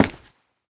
scpcb-godot/SFX/Step/Run8.ogg at c2afe78d7f60ab16ee83c3070b724c6066b420c6